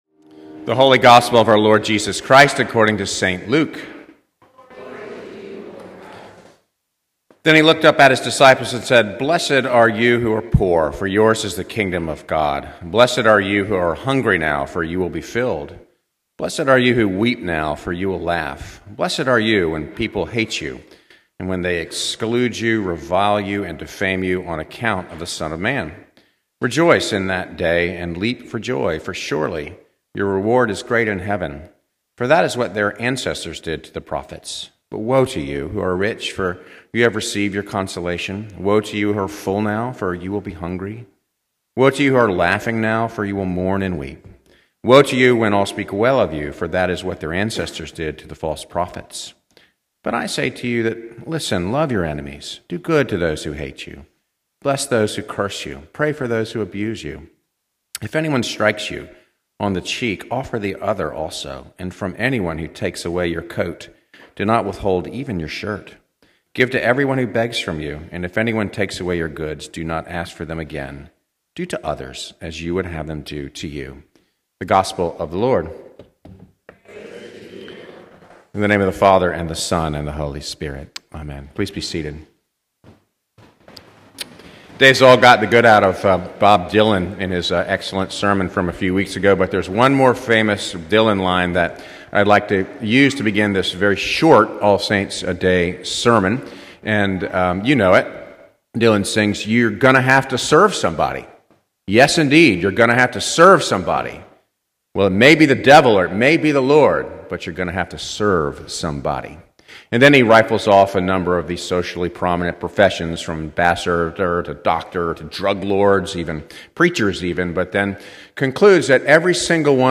Venue: Christ Church Charlottesville Scripture: Ephesians 1:11-23